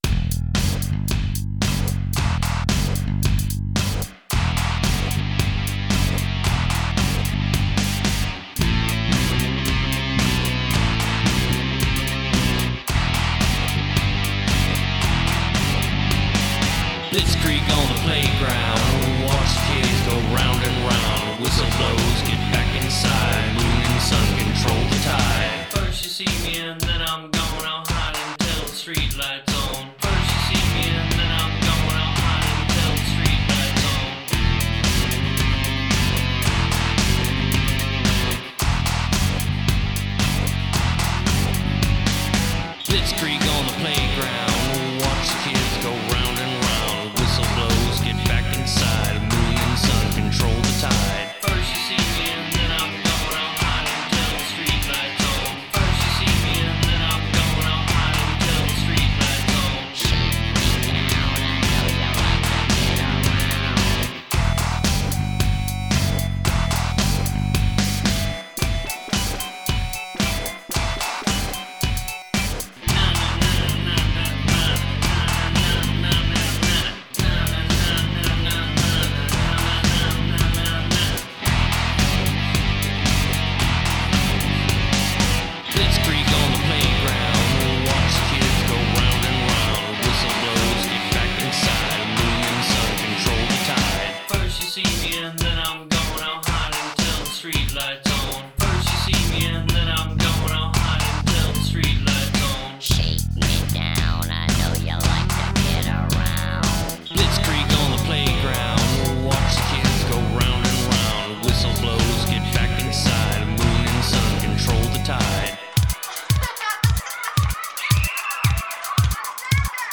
I can't seem to get the low-low-end down - anyway - let me know what you think...